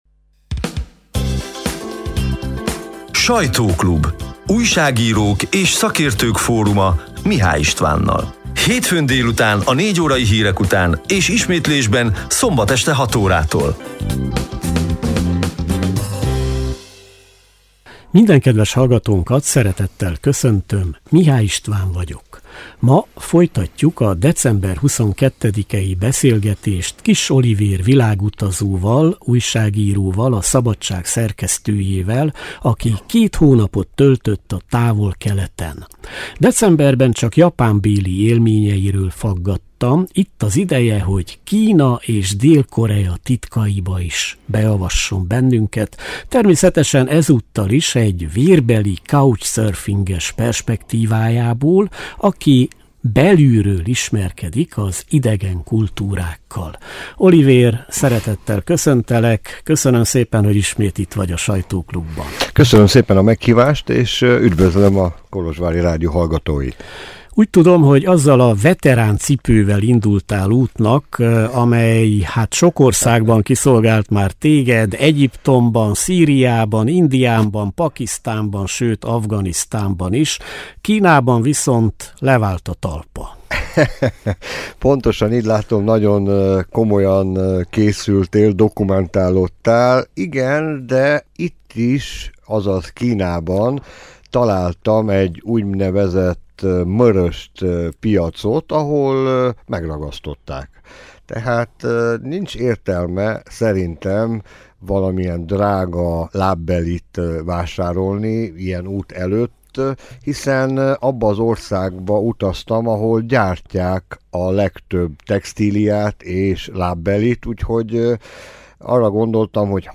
A lejátszóra kattintva a január 12-i , hétfő délutáni 55 perces élő műsor szerkesztett, kissé rövidített változatát hallgathatják meg.